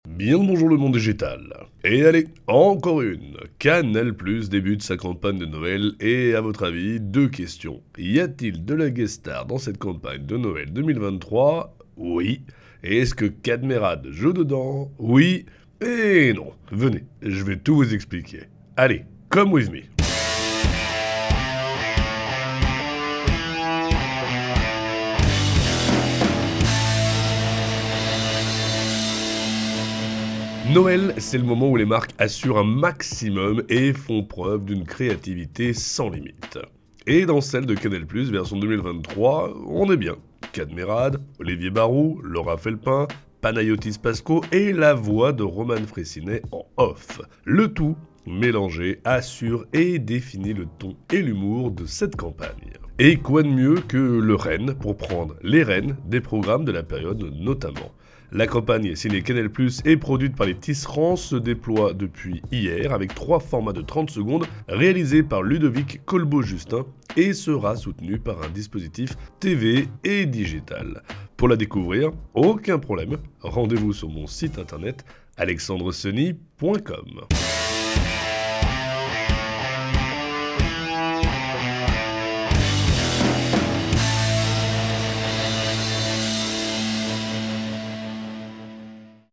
Et dans celle de Canal+ version 2023 on est bien : Kad Merad, Olivier Baroux, Laura Felpin, Panayotis Pascot et la voix de Roman Frayssinet en Off….le tout mélangé assure et définit le ton et l’humour de cette campagne..